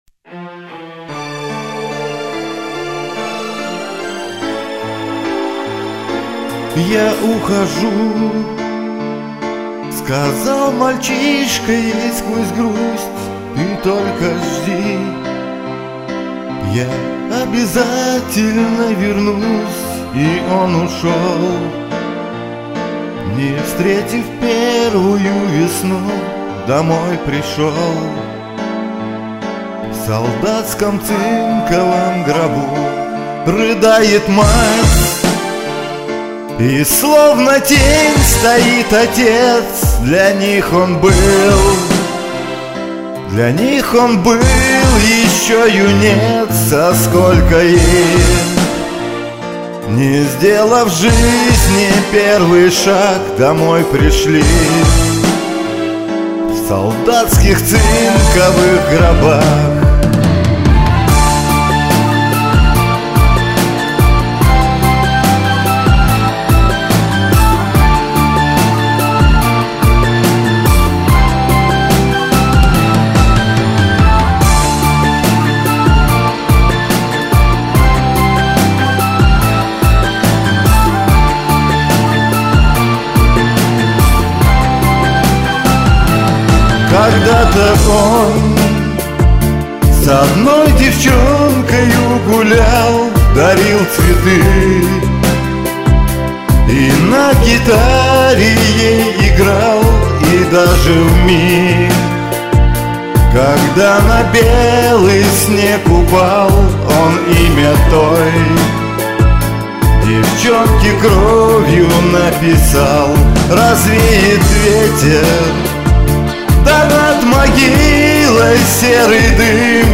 Резкости и решительности в голосе многовато.